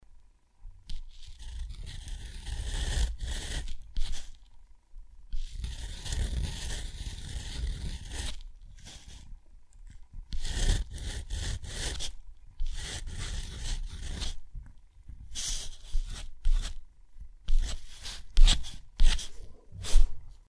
Здесь вы найдете скрип гусиного пера, шелест чернильных штрихов и металлического пера на бумаге.
8. Тщательно выводят буквы пером